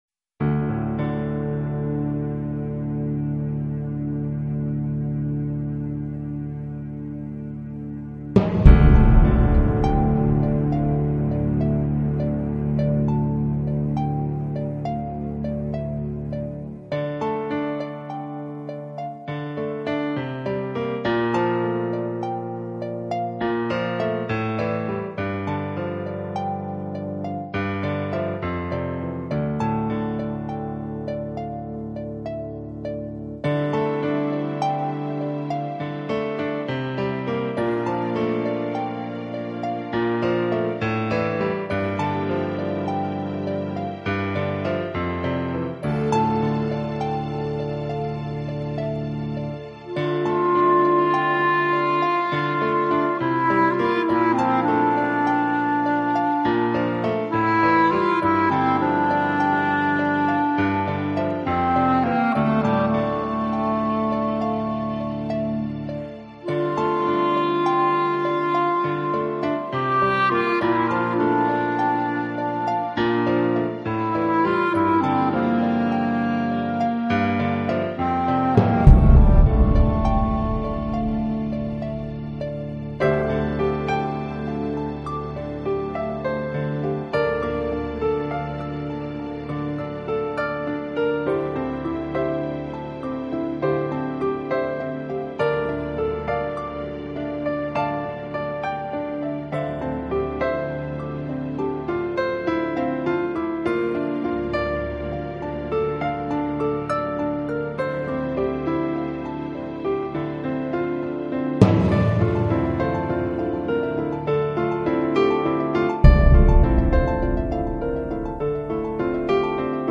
音乐类型：New Age / Piano Solo